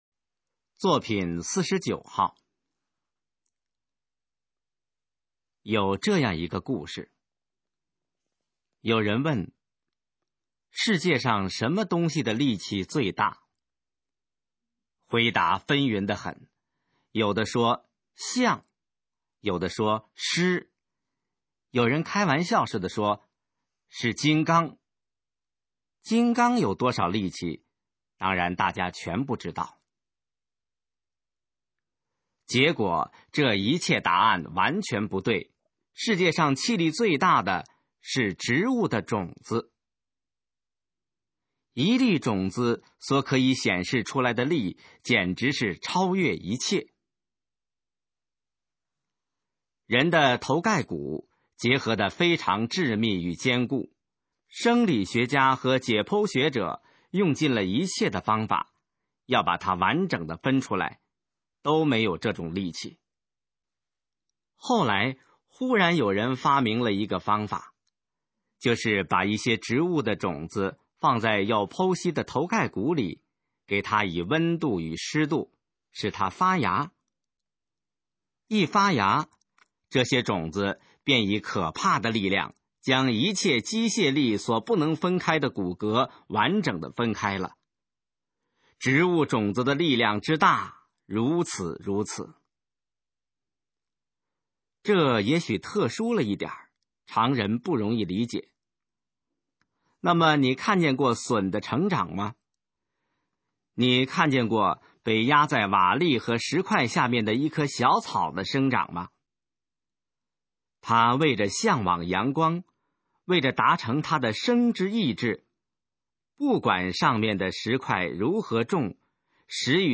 《野草》示范朗读_水平测试（等级考试）用60篇朗读作品范读